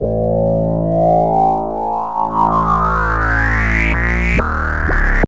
Weird.wav weird bass sound 57k